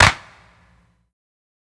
ILLMD015_CLAP_SAD.wav